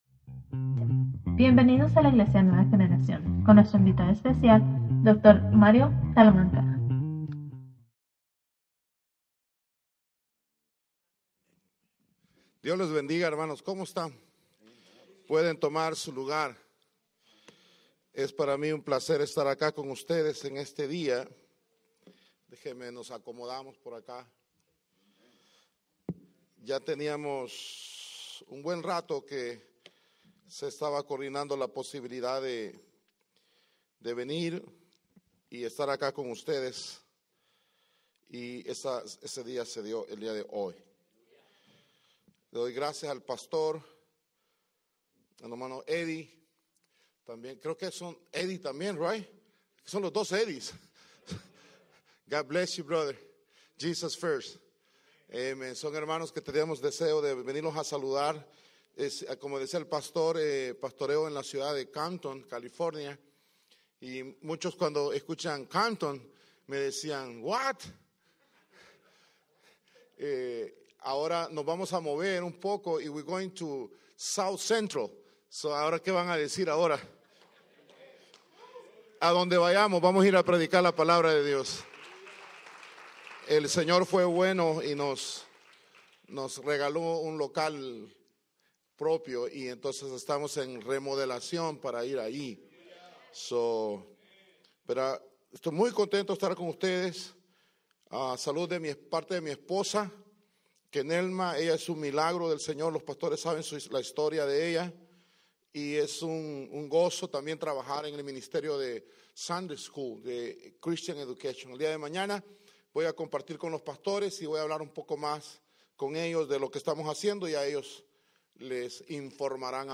Sermons | New Generation Church